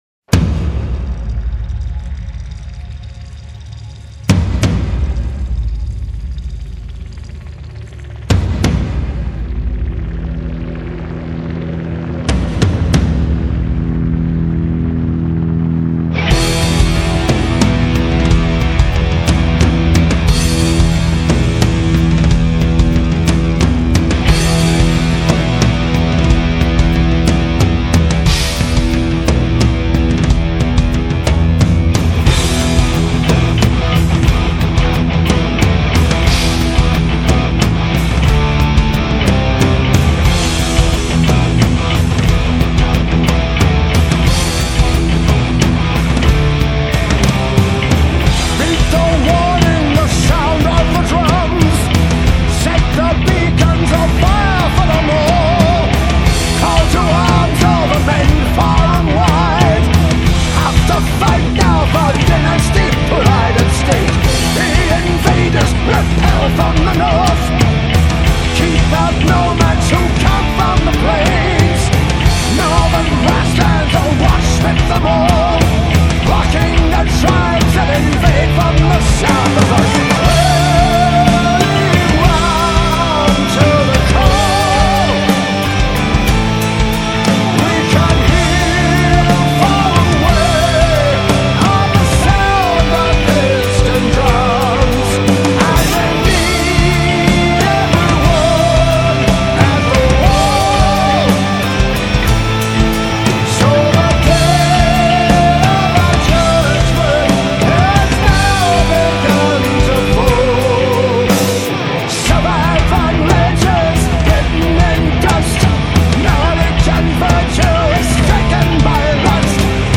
آلبوم هوی متال
Heavy Metal